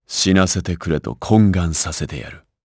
Lusikiel-Vox_Skill3_jp.wav